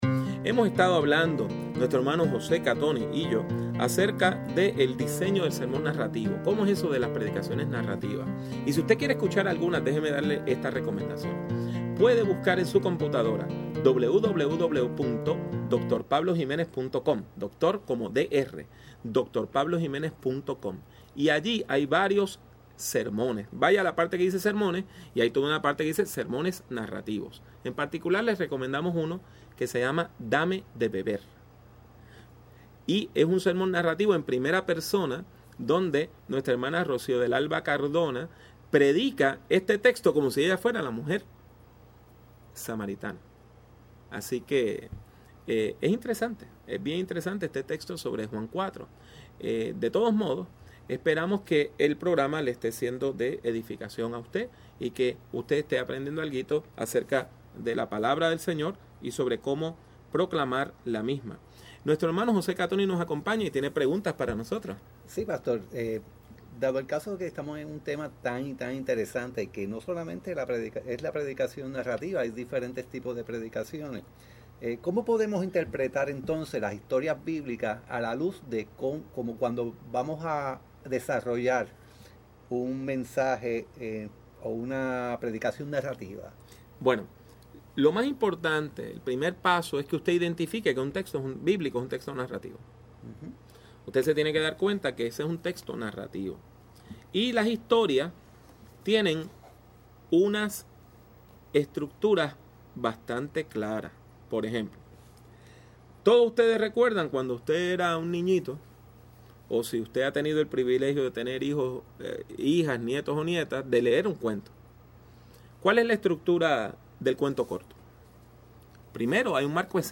Una conferencia contrastando dos estilos de predicación contemporáneos: el sermón deductivo tradicional y el inductivo.